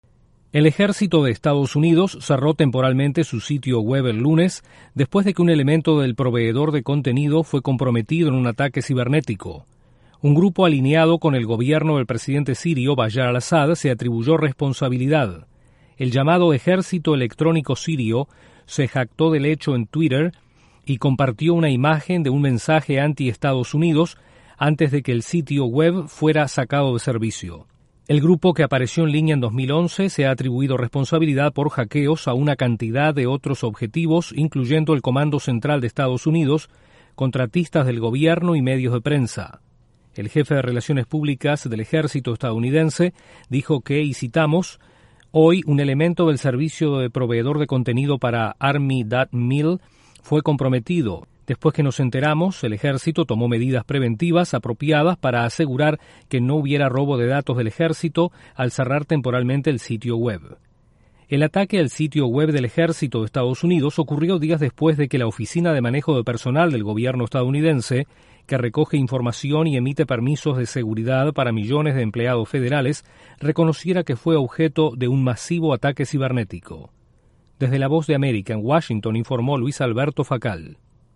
Hackers sirios atacan el sitio web del Ejército de Estados Unidos. Desde la Voz de América en Washington informa